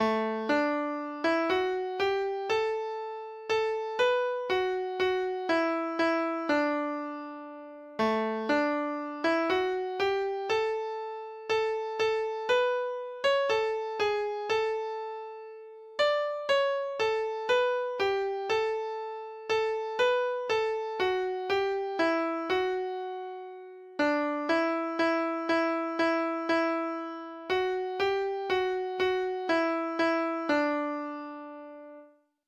Carl Nielsen
Lytt til data-generert lydfil